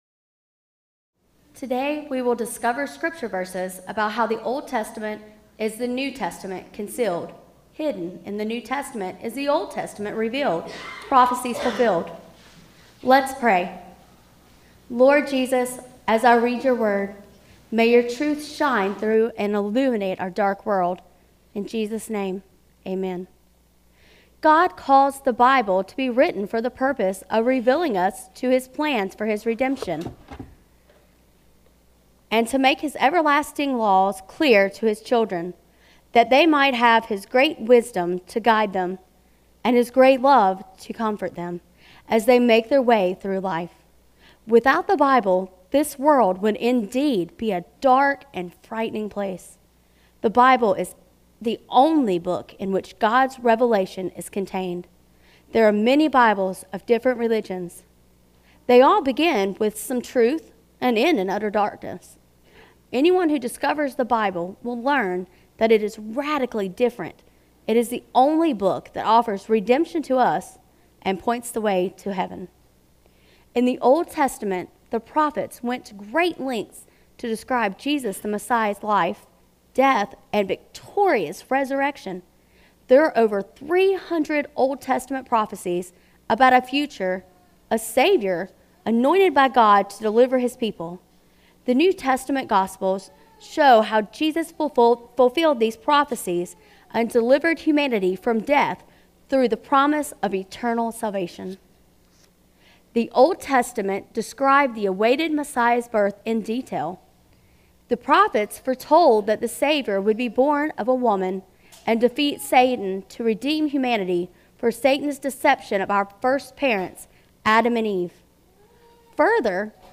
Service Audio
Children & Youth Christmas Presentation